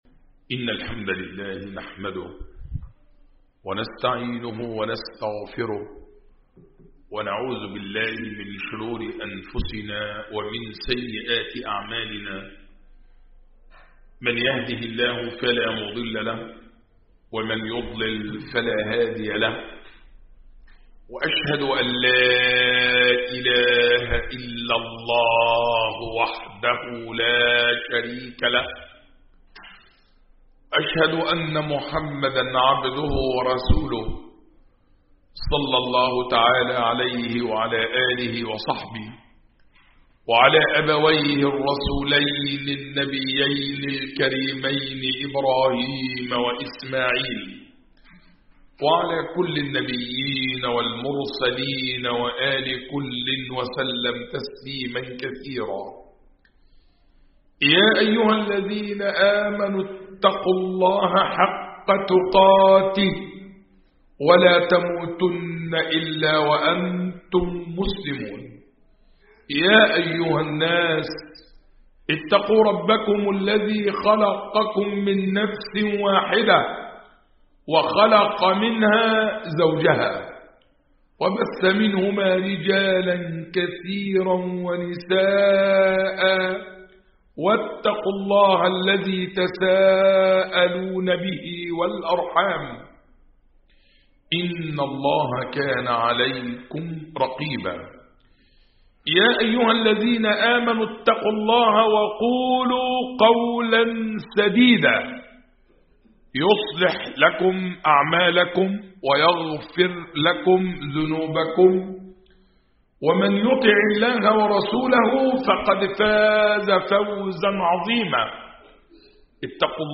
خطب الجمعة والأعياد